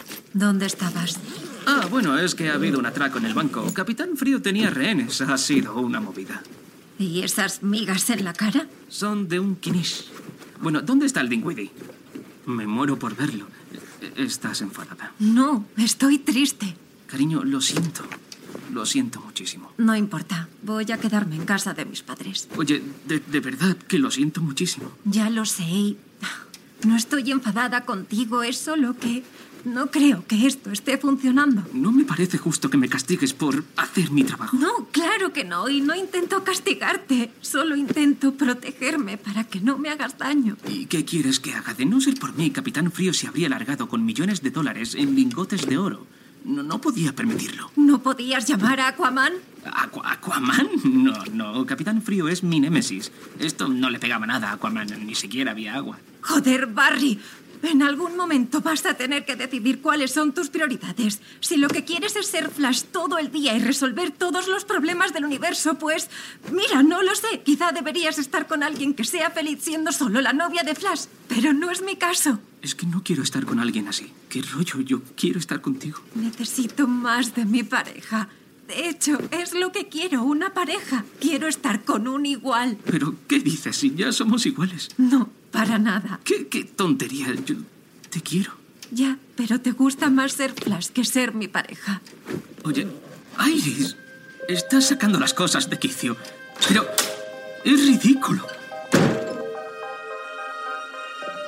Fragmento Ficción Sonora The Flash